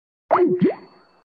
Play, download and share 1s_Skype end original sound button!!!!
1s-skype-end.mp3